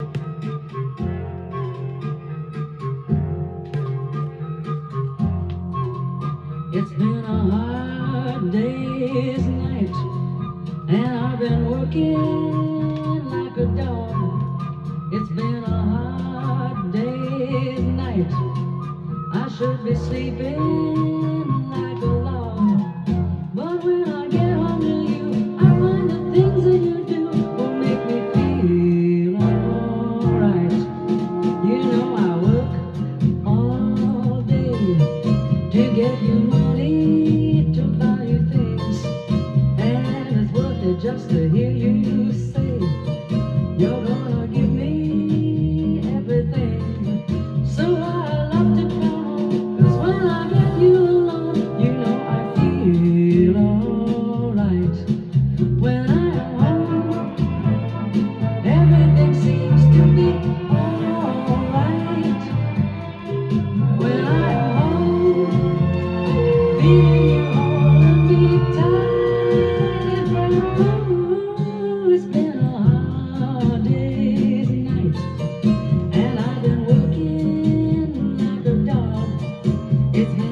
店頭で録音した音源の為、多少の外部音や音質の悪さはございますが、サンプルとしてご視聴ください。